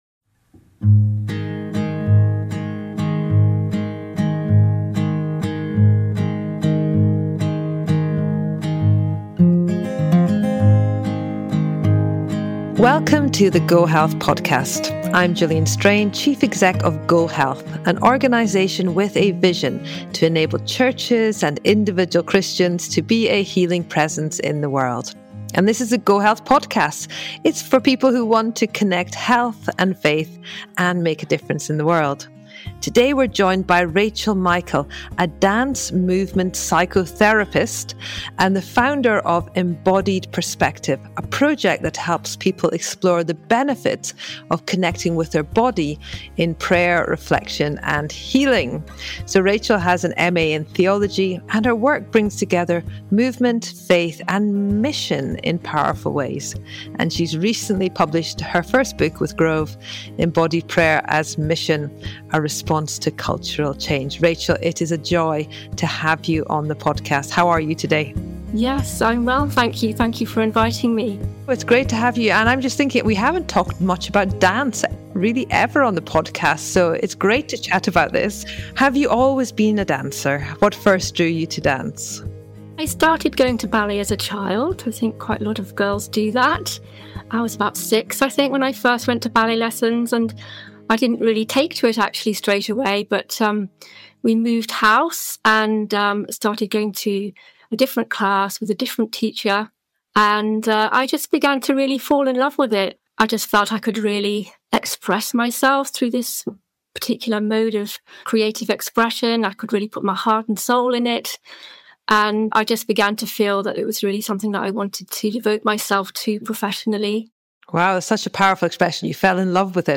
Faith meets science: The conversation celebrates how research on spirituality and neuroscience echoes ancient Christian wisdom about embodied prayer.